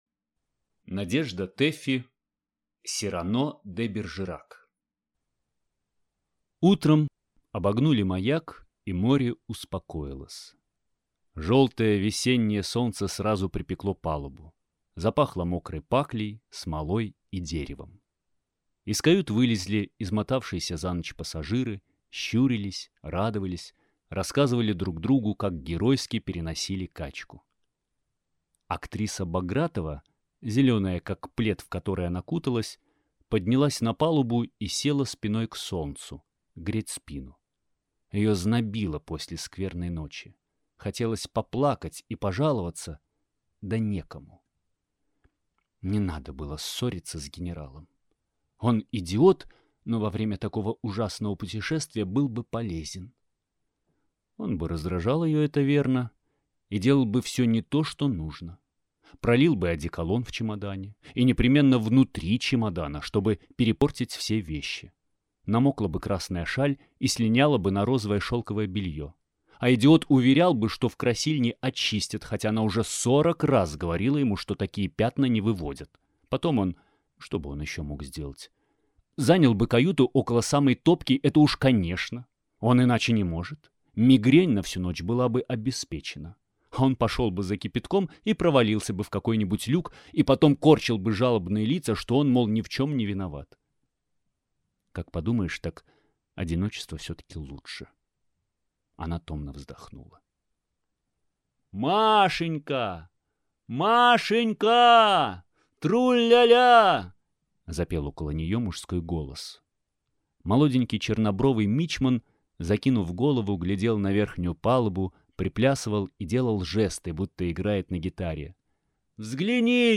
Аудиокнига Сирано де Бержерак | Библиотека аудиокниг